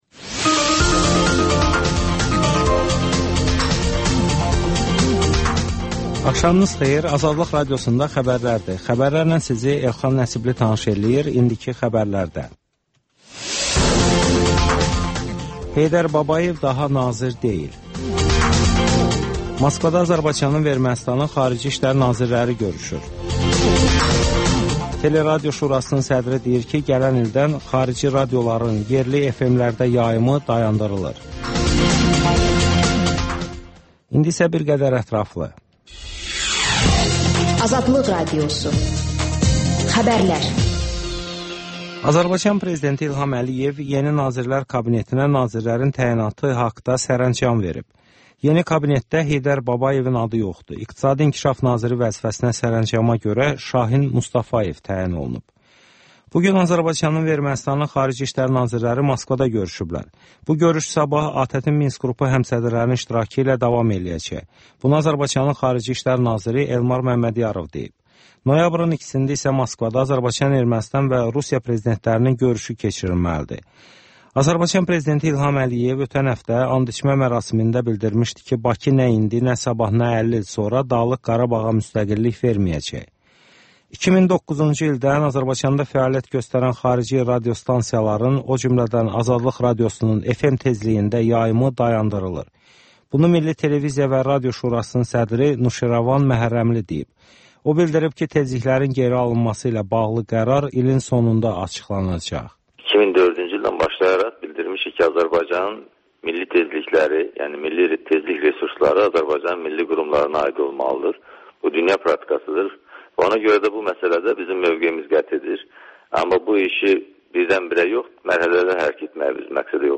Xəbərlər, müsahibələr, hadisələrin müzakirəsi, təhlillər, sonda isə HƏMYERLİ rubrikası: Xaricdə yaşayan azərbaycanlıların həyatı